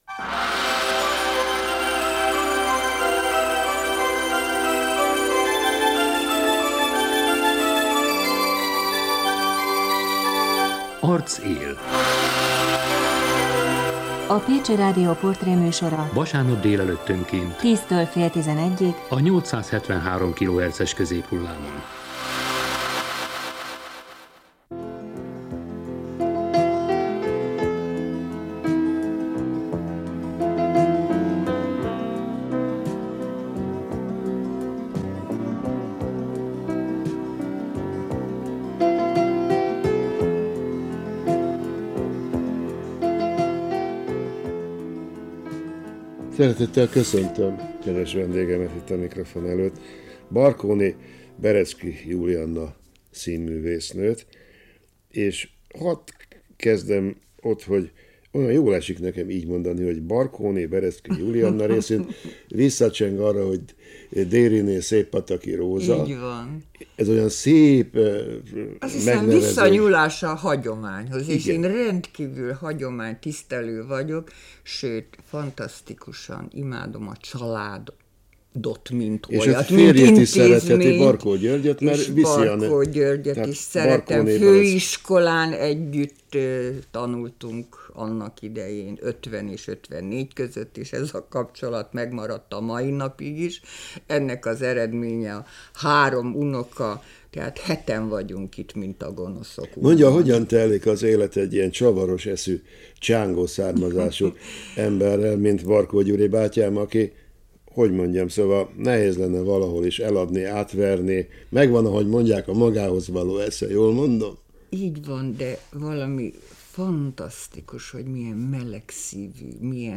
rádióadás